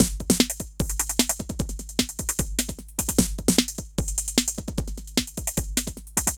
CRATE C DRUM 2.wav